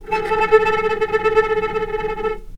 vc_trm-A4-pp.aif